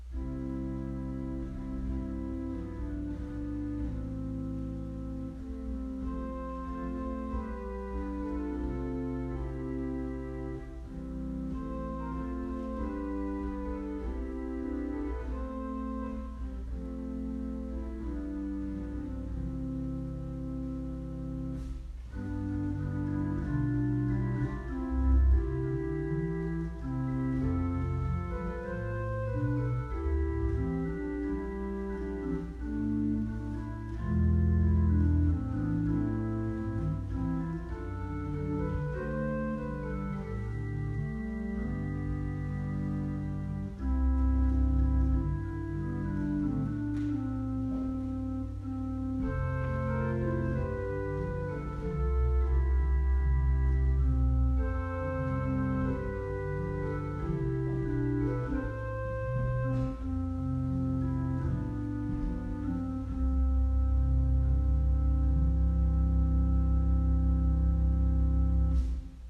MUSICAL INTERLUDE